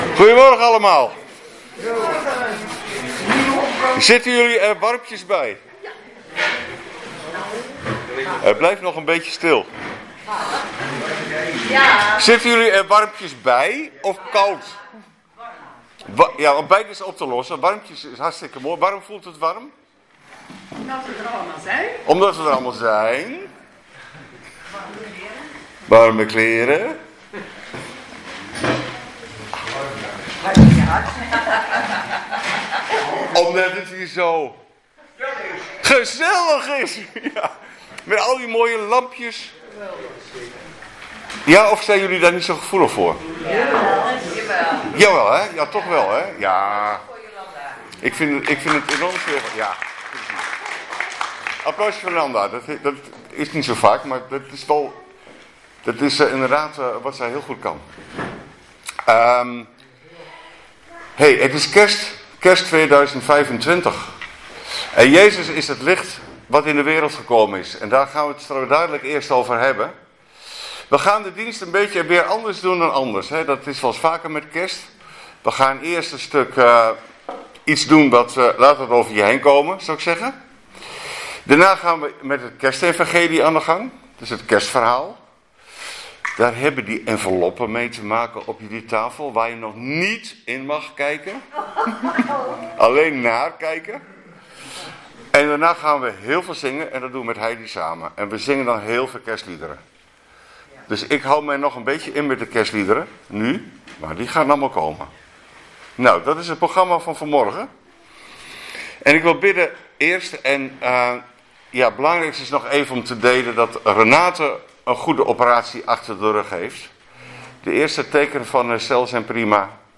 25 december 2025 kerstdienst - Volle Evangelie Gemeente Enschede